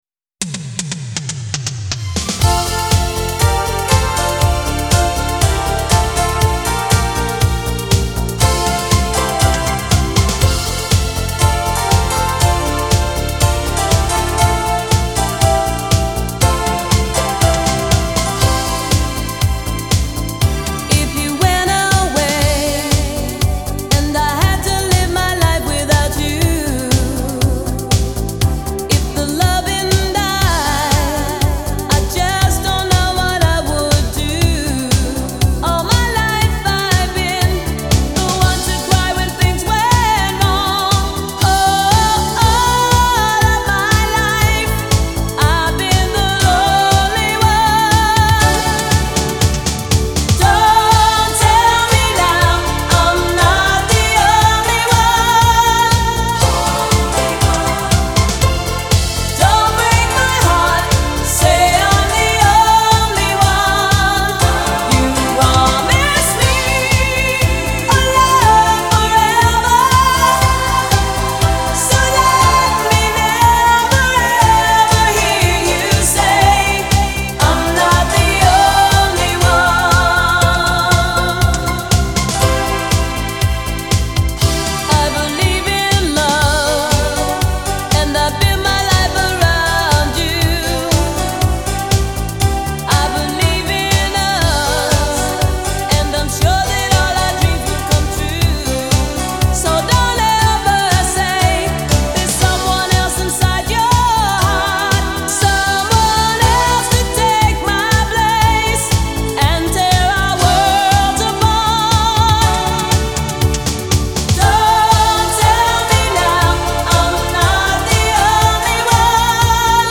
Genre : Disco